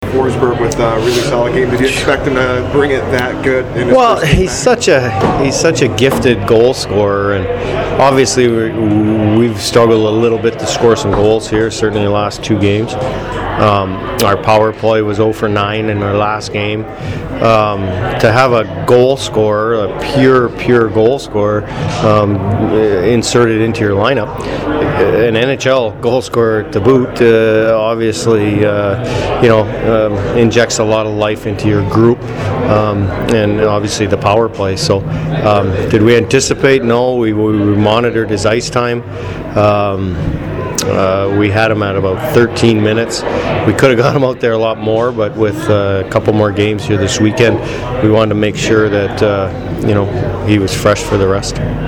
After the game I managed to catch up with Dean Evason, Filip Forsberg, and Scott Darling who gave their thoughts on the game.